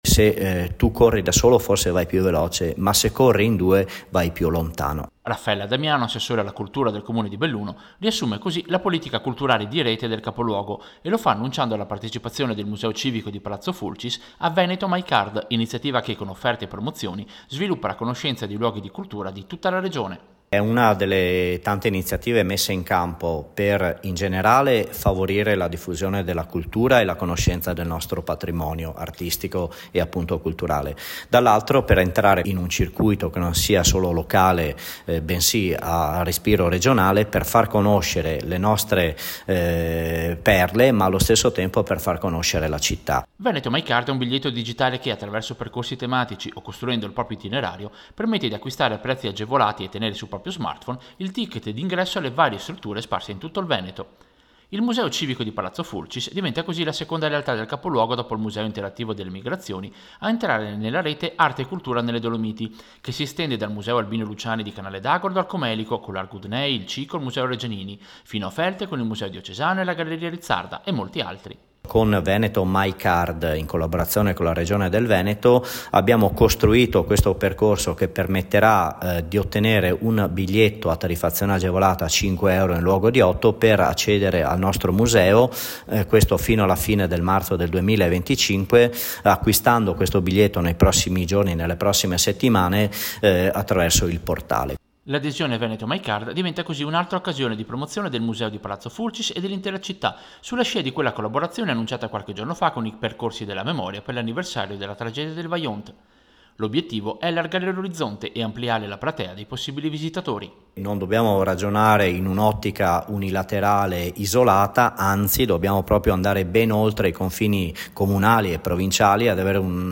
Servizio-Museo-Fulcis-Veneto-MyCard.mp3